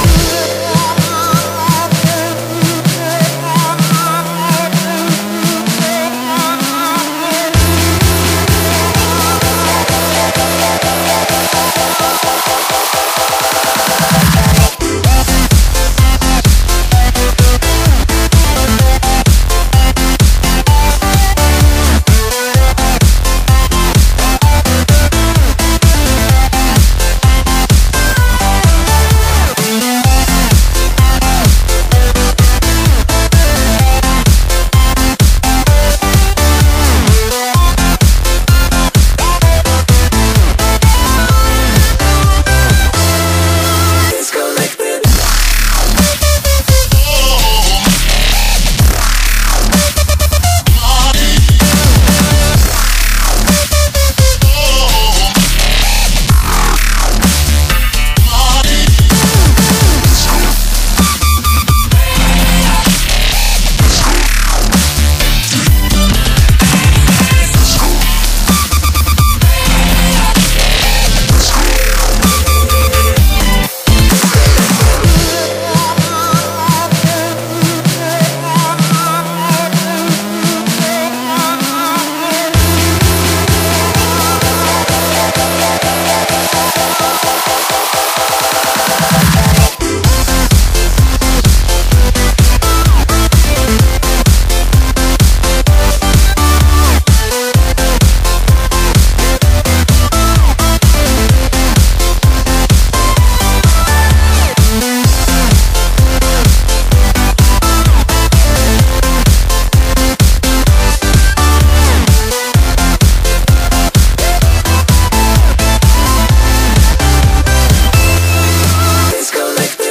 BPM64-128
Audio QualityPerfect (High Quality)